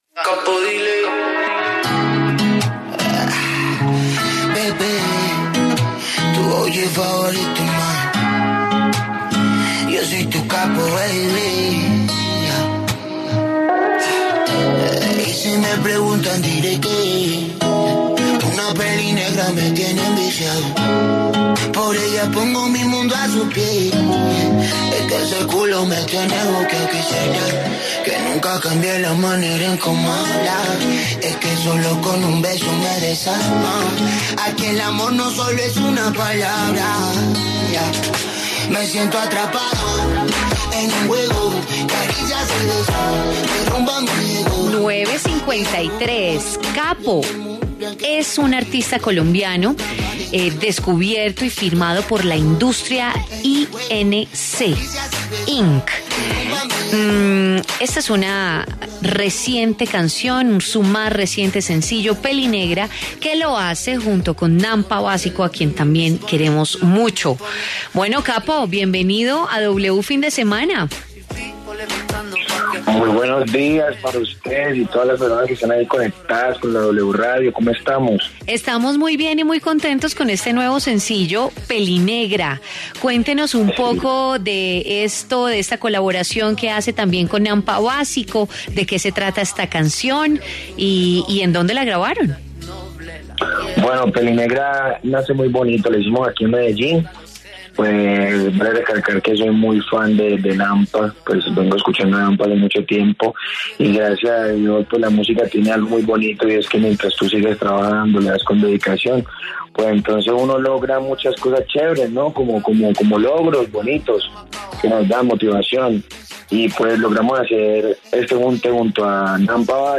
En W Fin de Semana, Kapo habla sobre el vertiginoso ascenso de su carrera musical y de sus actuales proyectos.